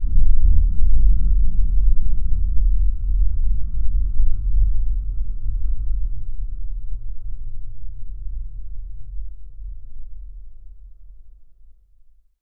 sci-fi_sub_bass_rumble_01.wav